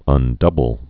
(ŭn-dŭbəl)